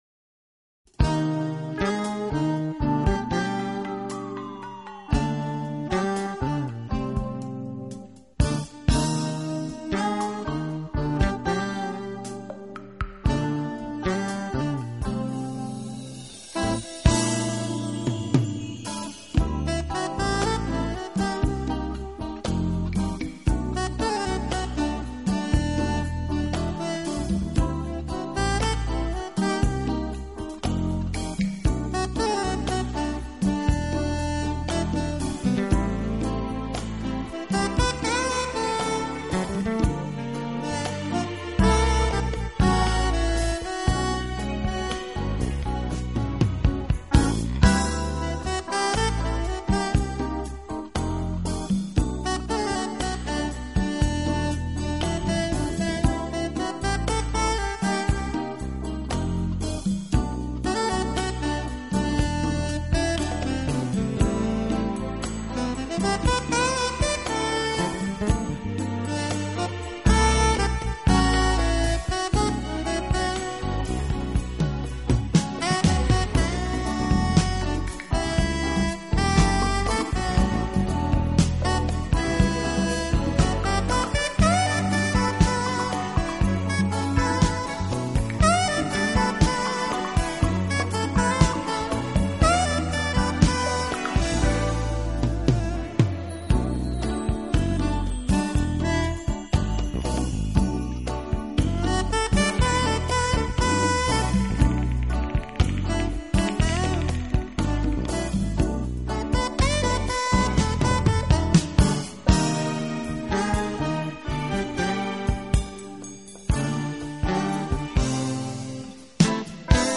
【爵士萨克斯】